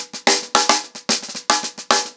TheQuest-110BPM.23.wav